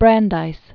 (brăndīs, -dīz), Louis Dembitz 1856-1941.